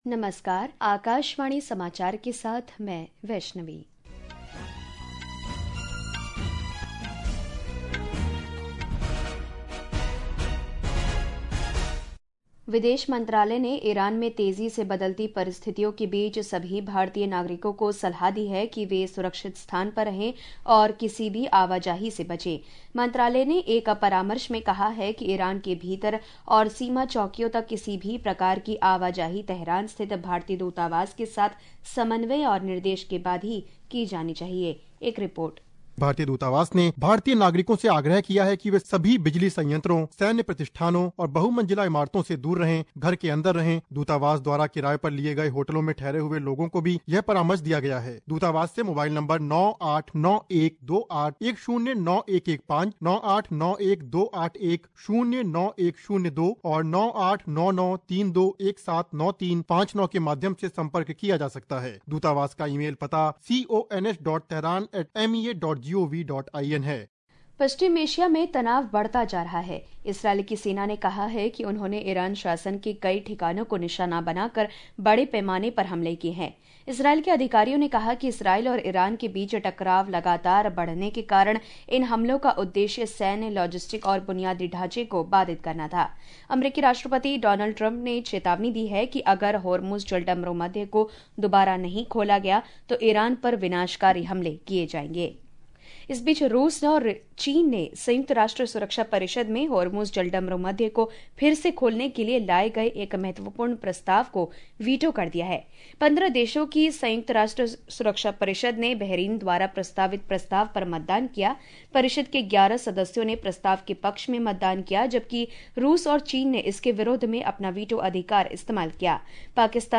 प्रति घंटा समाचार